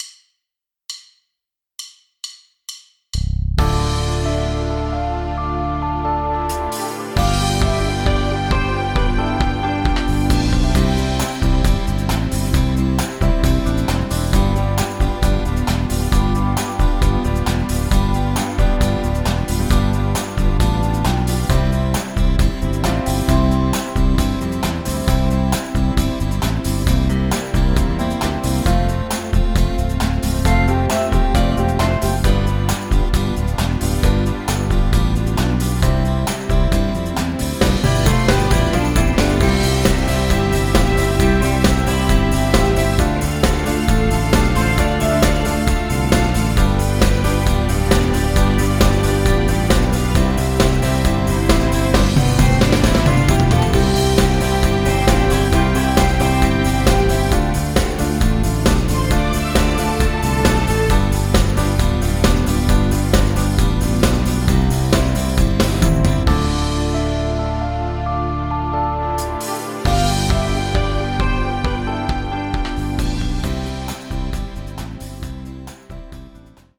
Karaoke, Instrumental